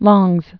(lôngz, lŏngz)